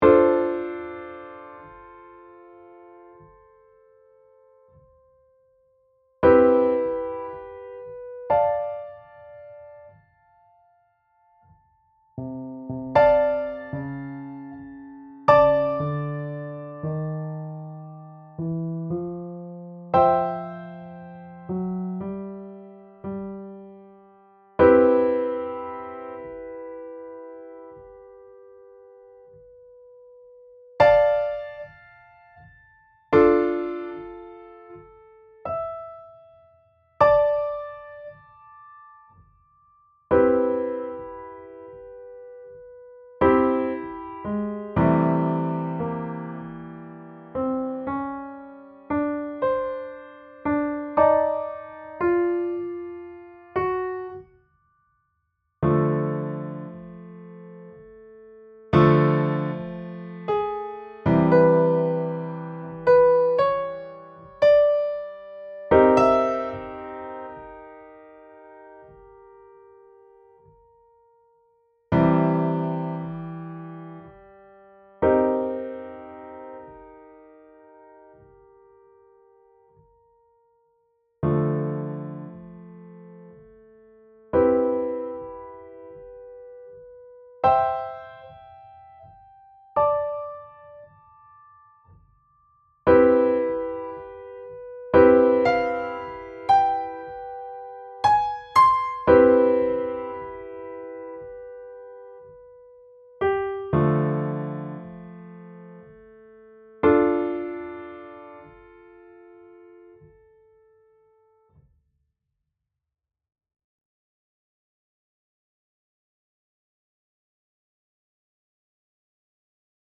Press Release Automation Recording